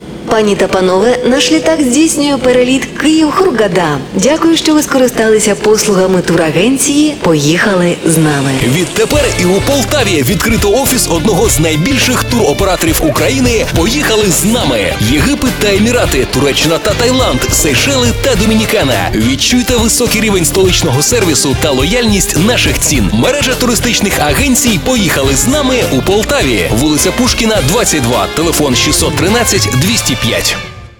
Игровой аудиоролик "Поехали с нами" Категория: Аудио/видео монтаж
Разработка и запись игрового аудиоролика для тур-оператора "Поехали с нами".